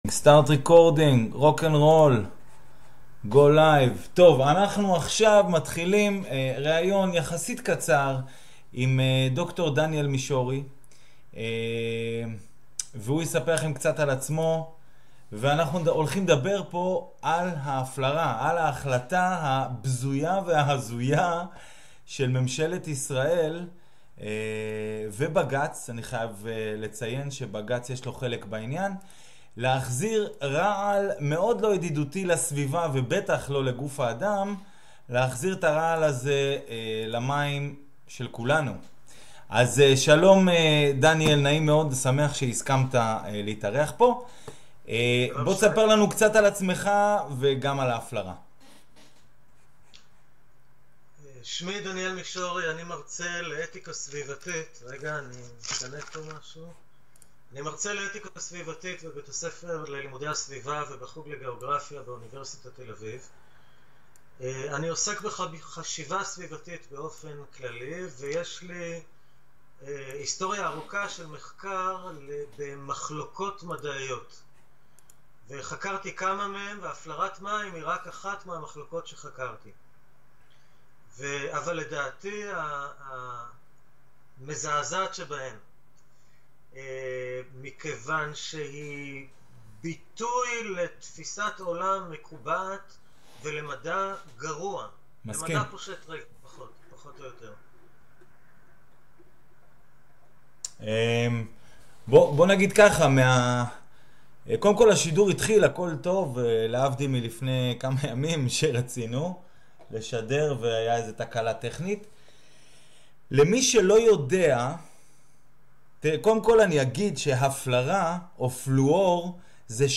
שיחה על הפלרה